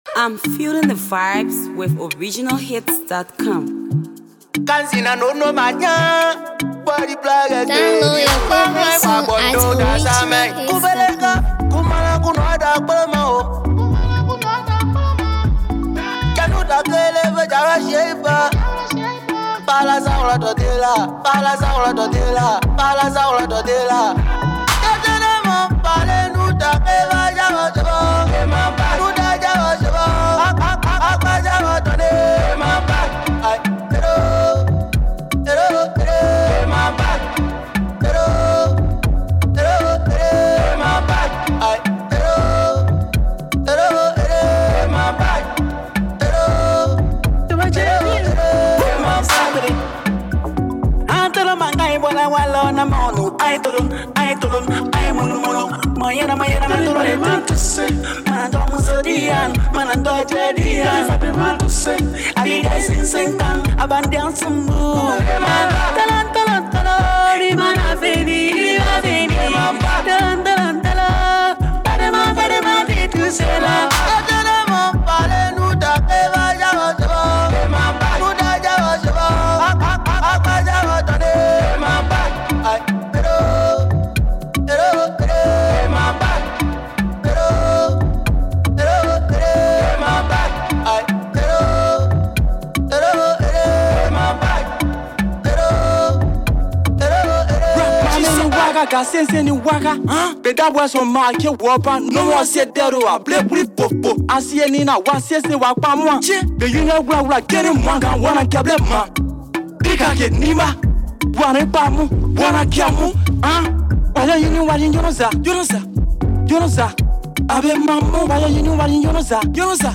” reflects the traditional play of the Kpelleh people.